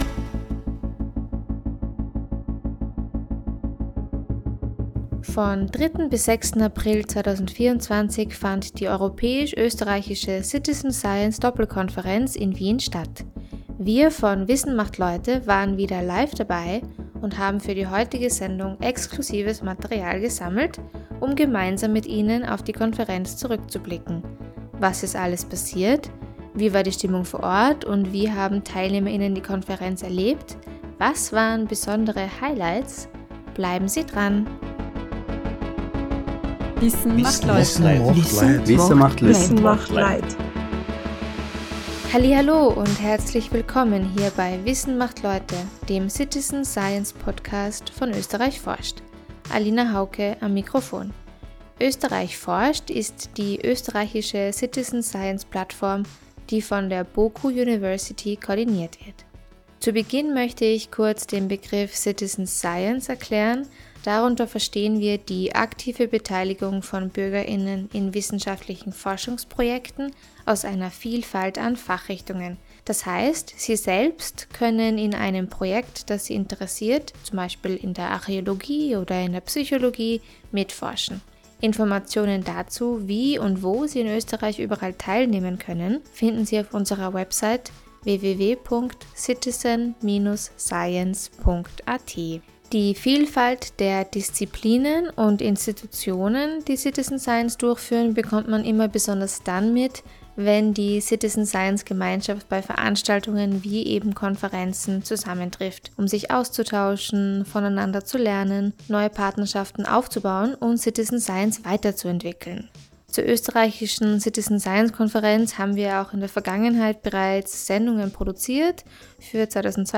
Beschreibung vor 1 Jahr Von 3.-6. April 2024 fand die Citizen Science Doppelkonferenz an der BOKU University und am Naturhistorischen Museum Wien statt. Wir von “Wissen macht Leute” waren live dabei und haben für die heutige Sendung exklusives Material gesammelt: unter anderem berichten 10 Personen von ihren Erfahrungen bei der Konferenz. Außerdem hören Sie in dieser Folge, wie die Konferenz abgelaufen ist, wie die Stimmung vor Ort war, welche Highlights es gab und vieles mehr.